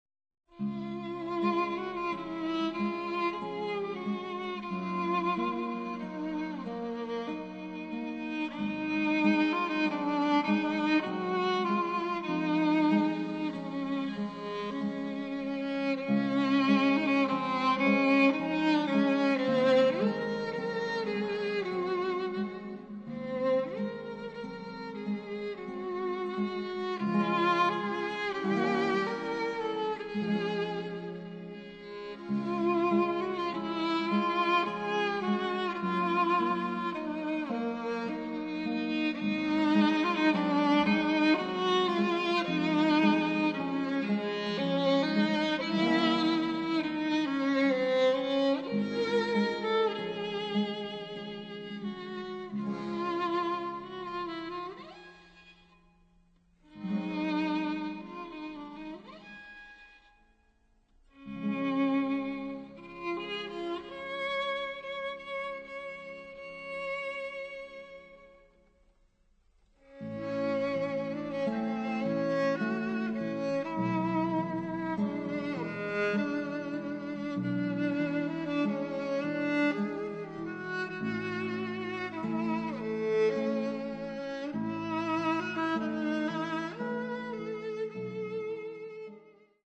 * Quartett mit Knopfharmonika